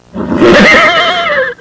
סוס1.mp3